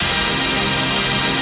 ATMOPAD14.wav